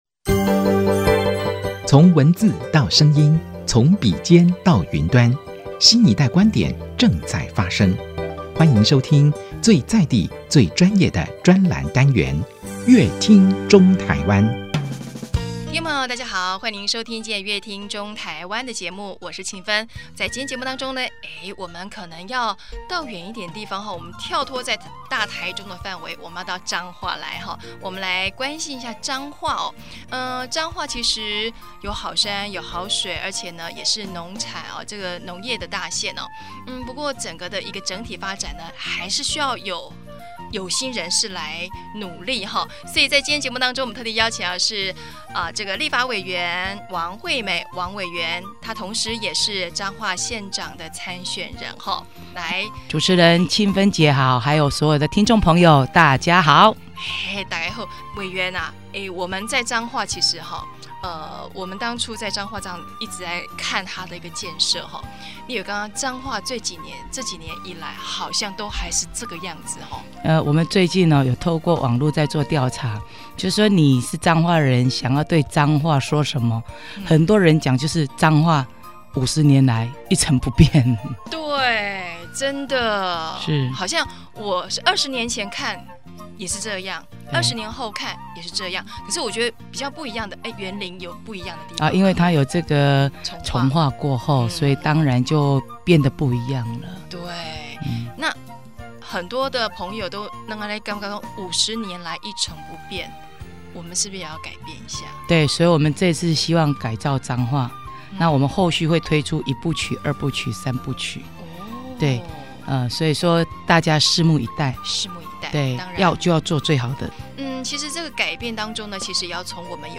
本集來賓：王惠美立法委員 本集主題：「從一成不變中改變彰化」 本集內容： 您對彰化的感覺是什麼呢?是農業大縣?